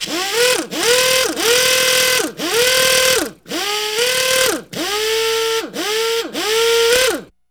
LUGNUT TO01L.wav